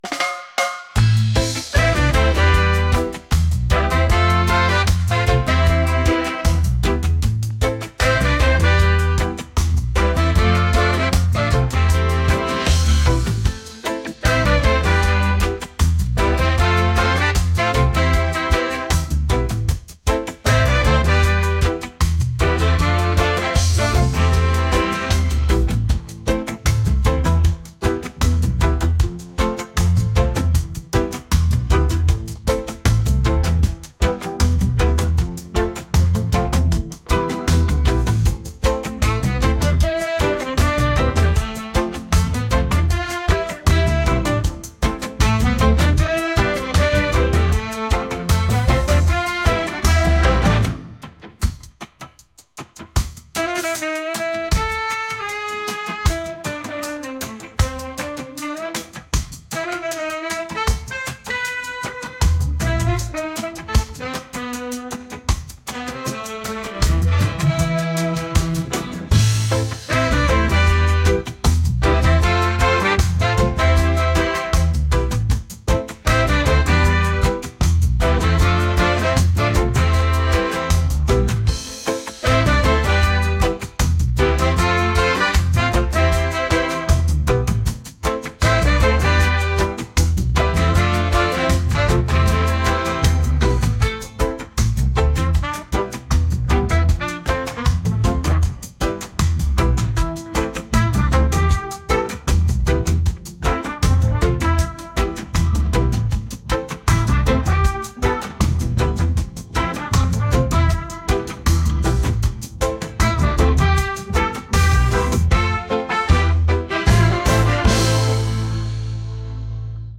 reggae | electronic